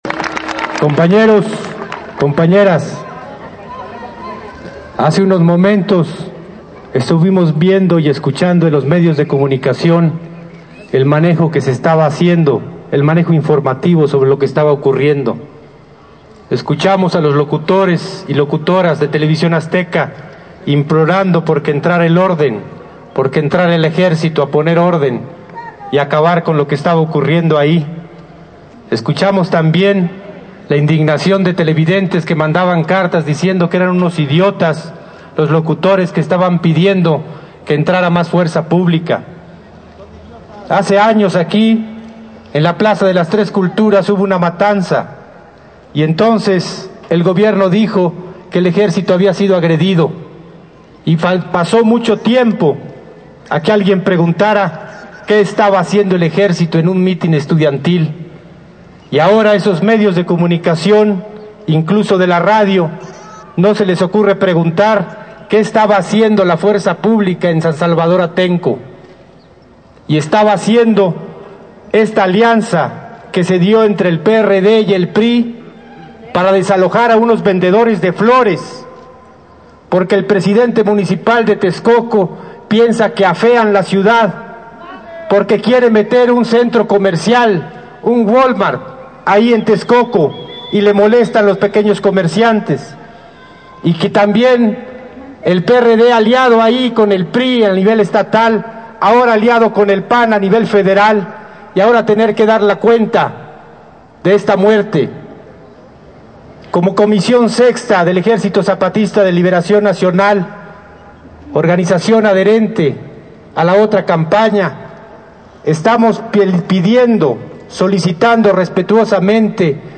“The municipal president wants to evict these people because he thinks their market leaves the area dirty and he wants to put a commercial center, a Wal-mart in Texcoco,” Marcos said before thousands of people during a public rally in Tlatelolco.
marcostlatelolco.mp3